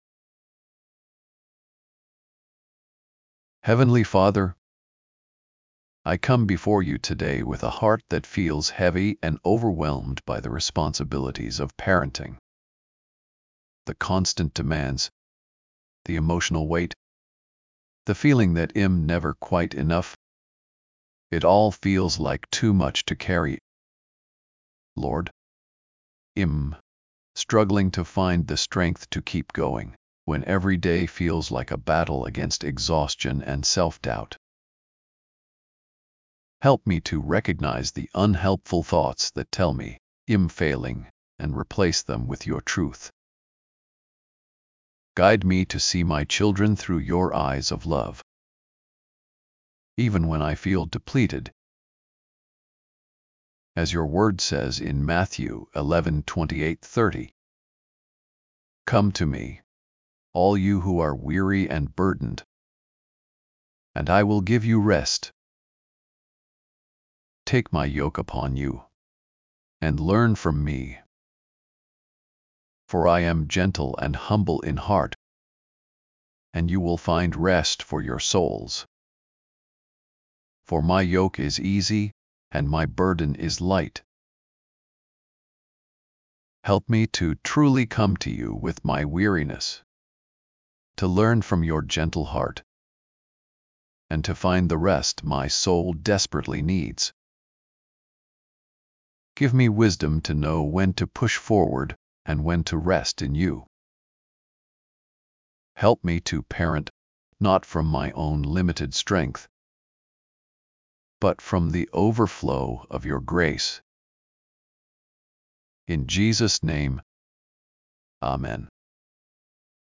1198 -1 Prayer Prayer mood:overwhelmed